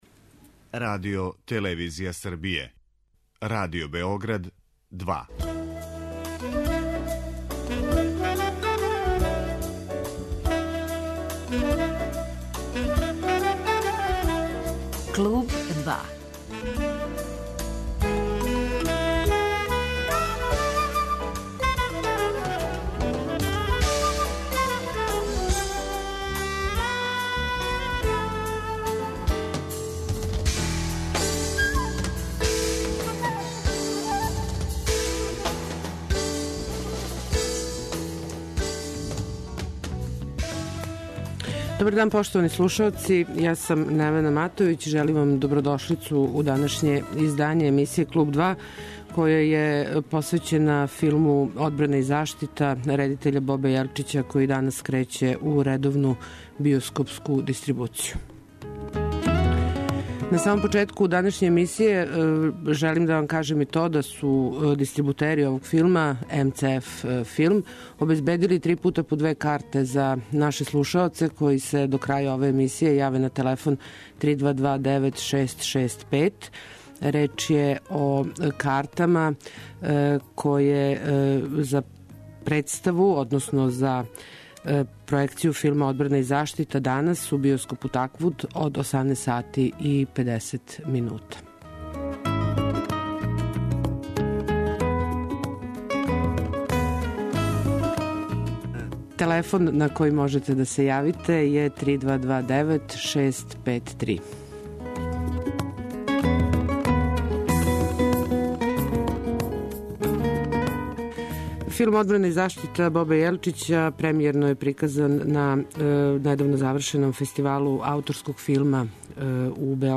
У данашњем Клубу 2 , слушате разговоре снимљене на 60. Филмском фестивалу у Пули, на коме је "Одбрана и заштита" добила седам "Златних арена".